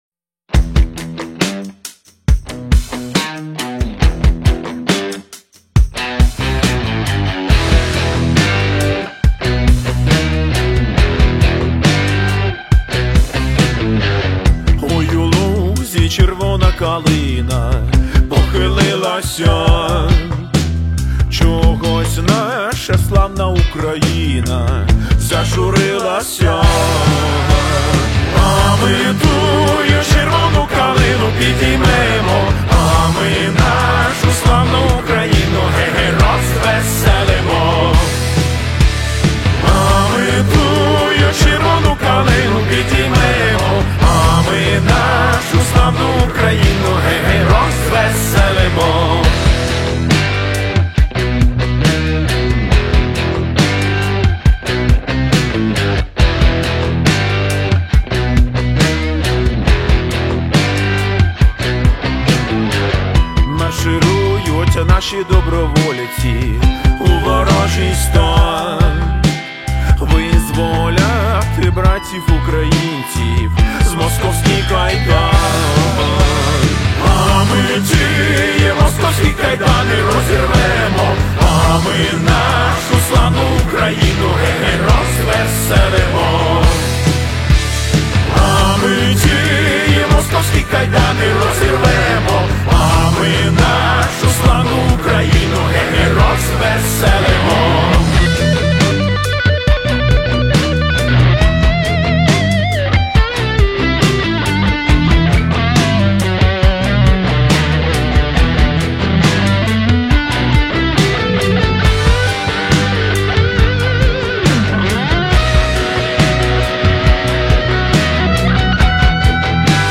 Жанр: Рок , Ремікси